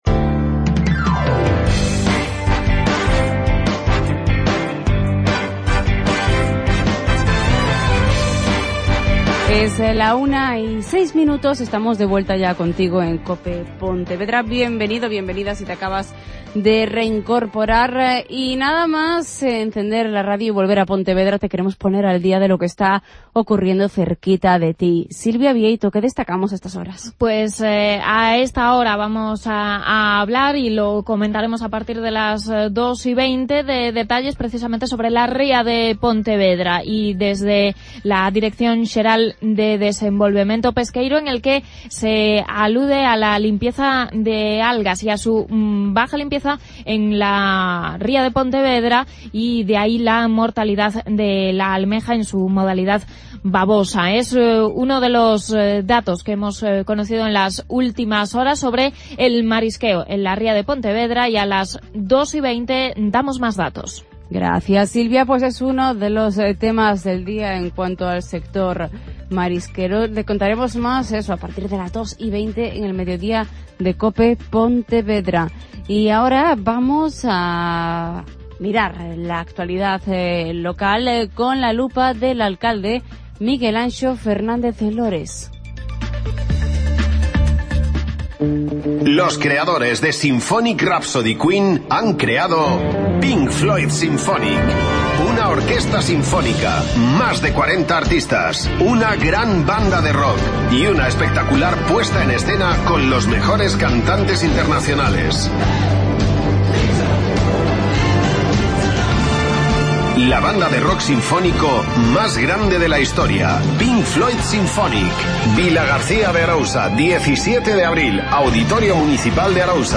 Alcalde de Pontevedra.